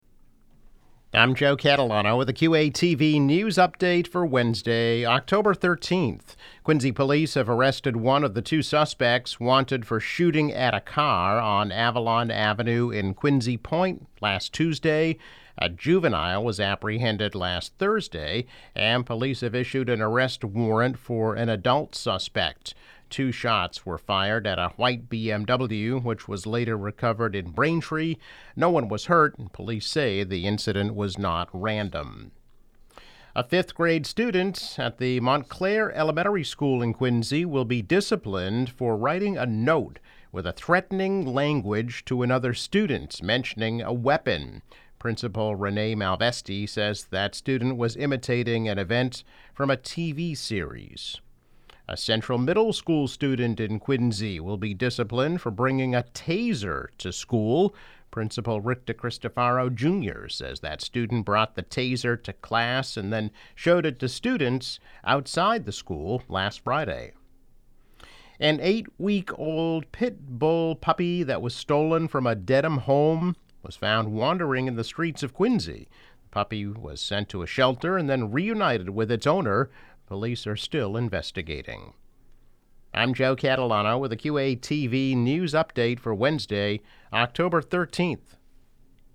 News Update - October 13, 2021